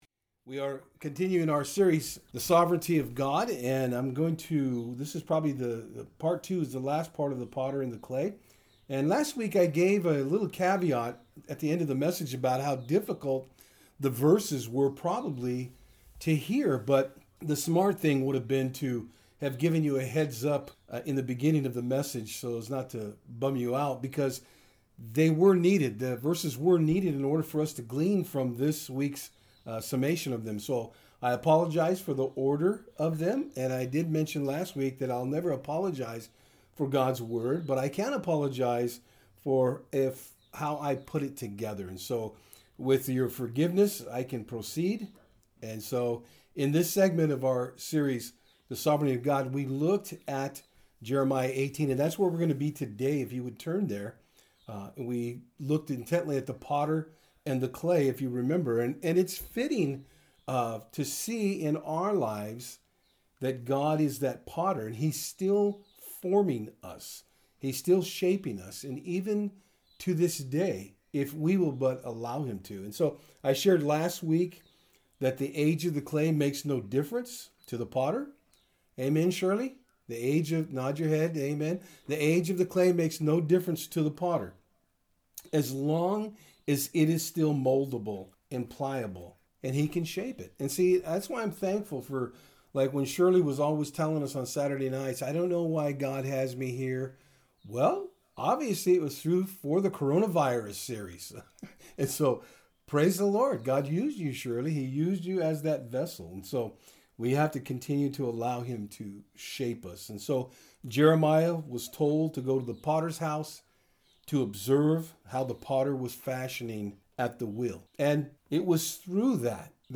Service Type: Sundays @ Fort Hill